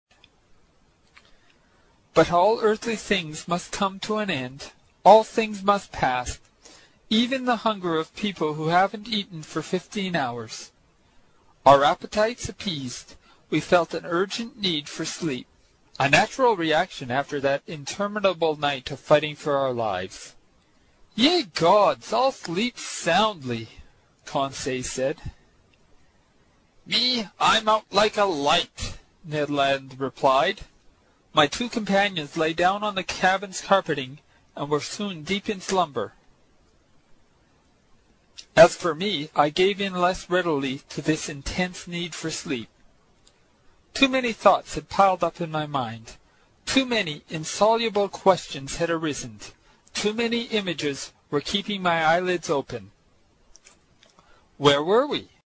英语听书《海底两万里》第114期 第8章 动中之动(17) 听力文件下载—在线英语听力室